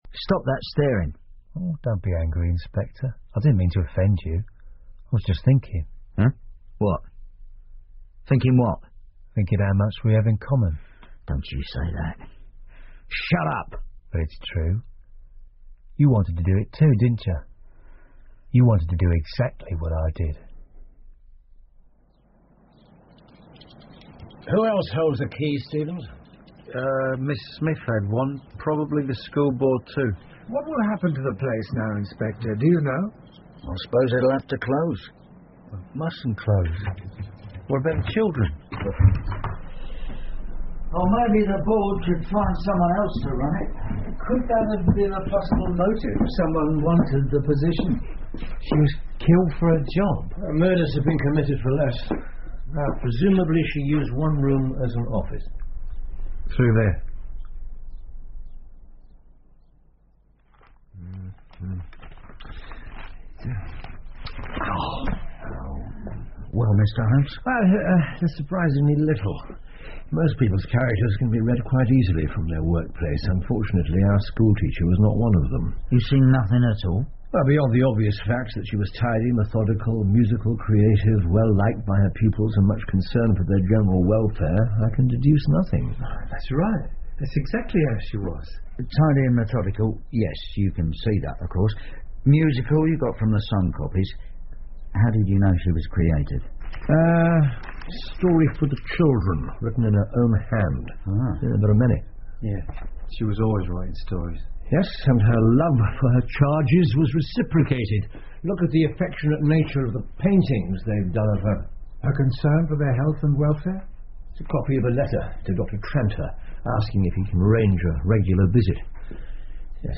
福尔摩斯广播剧 The Shameful Betrayal Of Miss Emily Smith 5 听力文件下载—在线英语听力室